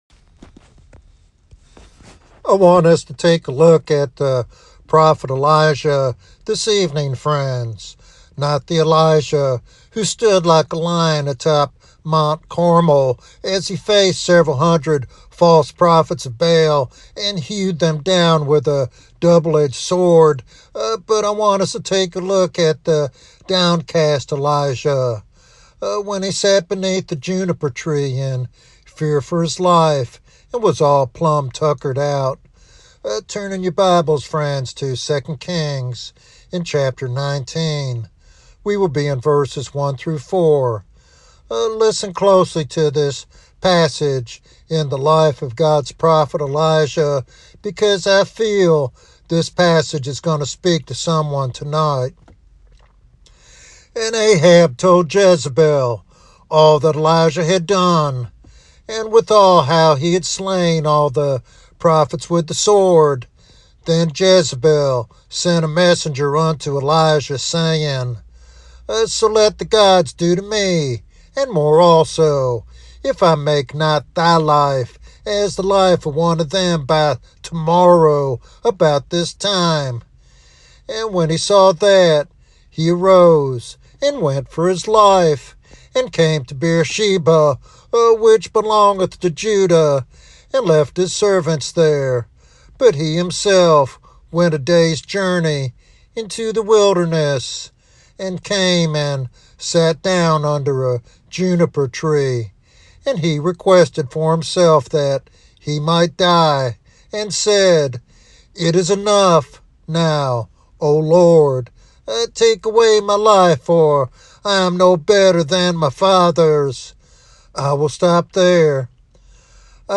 This devotional sermon offers practical hope and spiritual encouragement for those feeling overwhelmed in their calling.